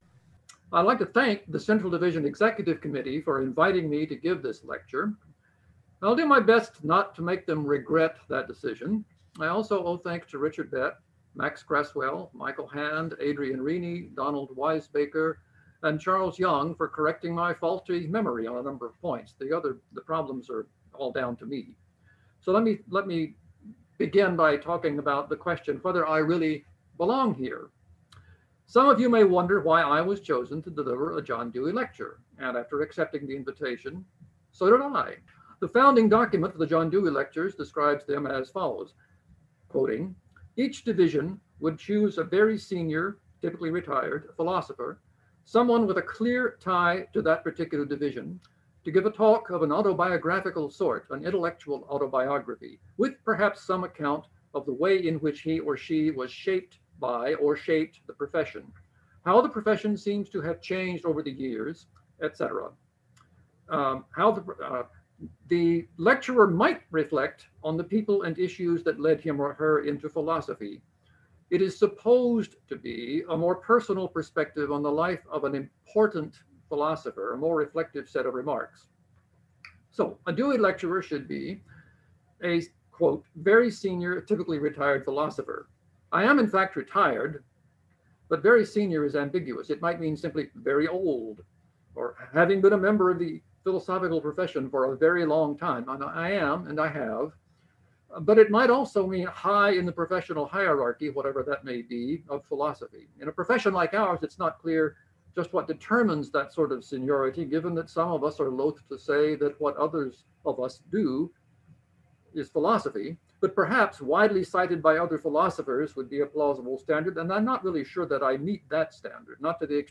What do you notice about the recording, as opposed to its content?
” given at the 2021 Central Division Meeting.